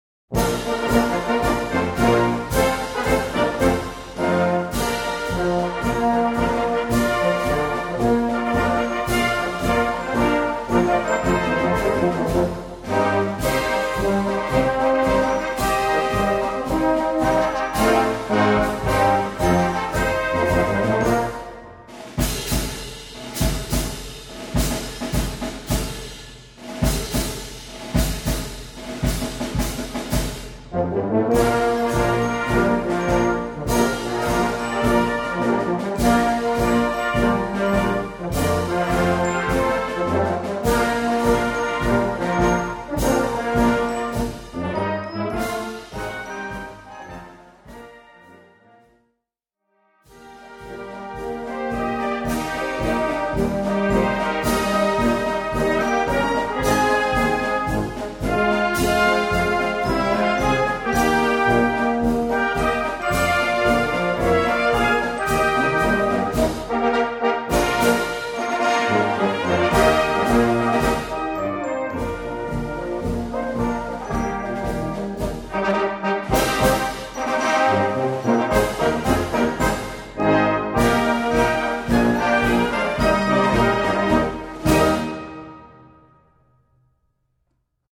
3:00 Minuten Besetzung: Blasorchester PDF